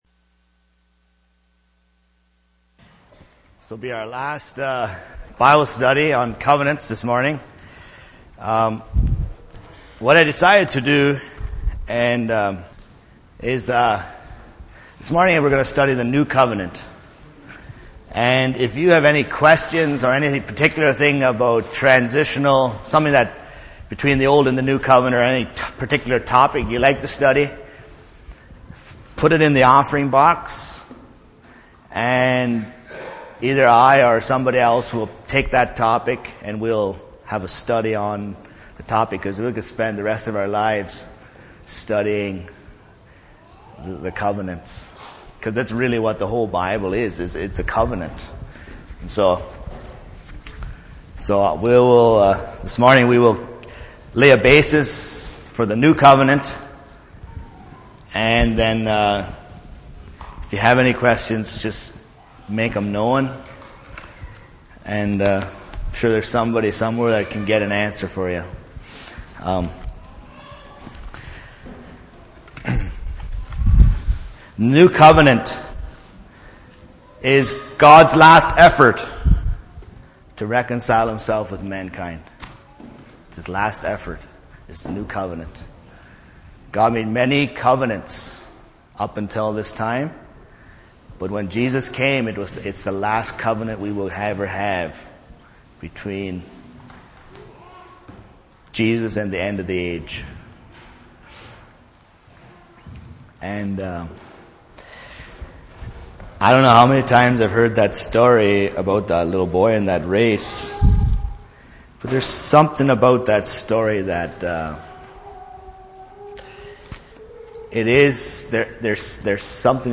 Sunday Morning Bible Study Service Type: Sunday Morning %todo_render% « When Jesus Breaks Bread Biblical Church Administration